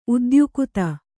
♪ udyukuta